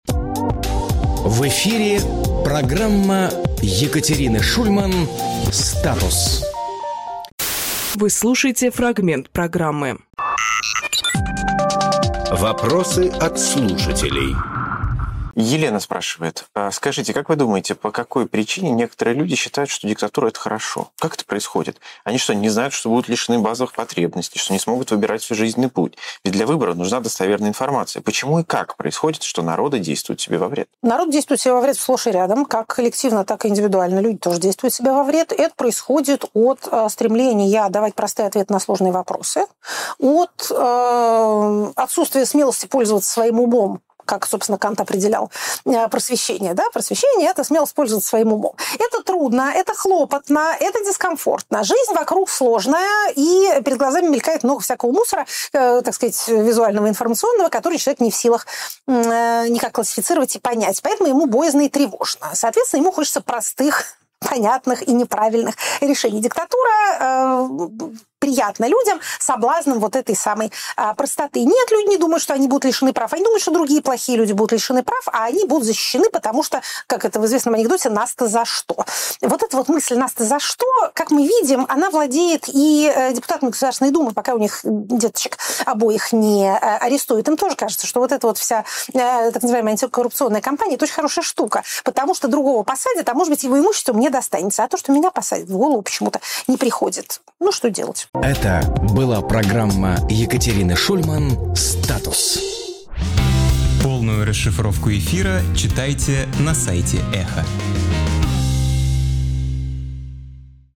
Екатерина Шульманполитолог
Фрагмент эфира от 03.02.26